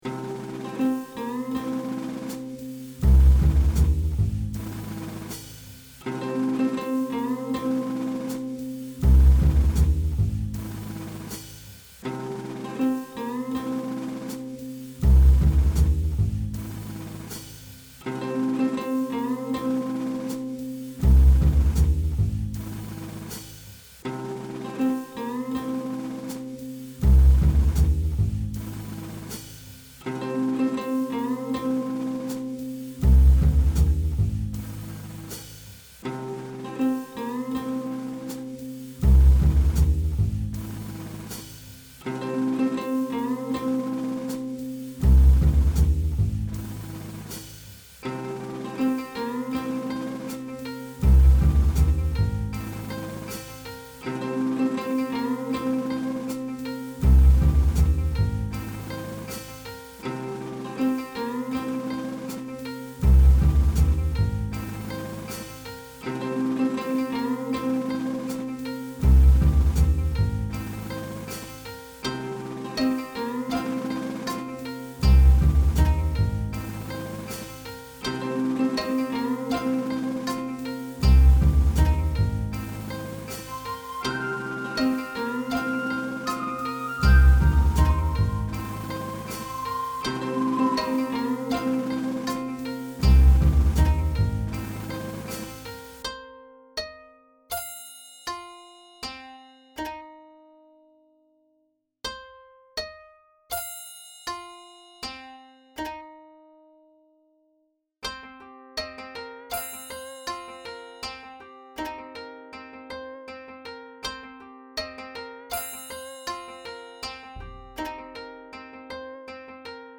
Heroic themed soudscape